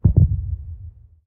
Minecraft Version Minecraft Version 25w18a Latest Release | Latest Snapshot 25w18a / assets / minecraft / sounds / mob / warden / heartbeat_1.ogg Compare With Compare With Latest Release | Latest Snapshot
heartbeat_1.ogg